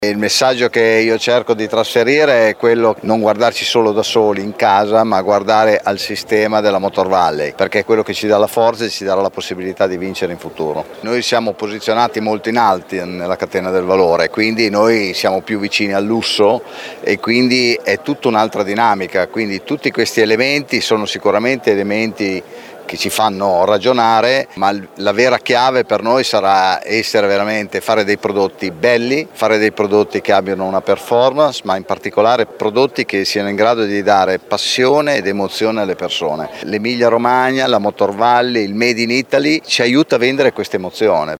Le interviste ai protagonisti: